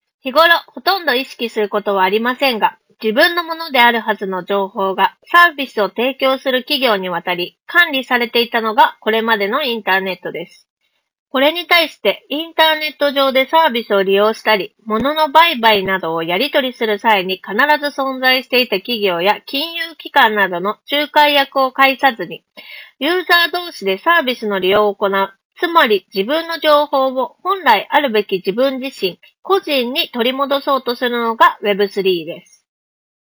ノイキャン効果は高く、周囲のノイズを効果的に取り除き、装着者の声のみをクリアに拾い上げることができていました。
▼QCY MeloBuds N70で収録した音声
iPhone 16 Proで撮影した動画と、イヤホン内蔵マイクで収録した音声を聴き比べてみると、内蔵マイクでは周囲の環境ノイズ（空調音、本に触れる音）などが効果的に低減されており、装着者の声のみを明瞭に拾い上げることができています。
さすがに専用のハイエンドマイクと比べると録音品質は劣るものの、普通に通話するには十分に優れた性能となっています。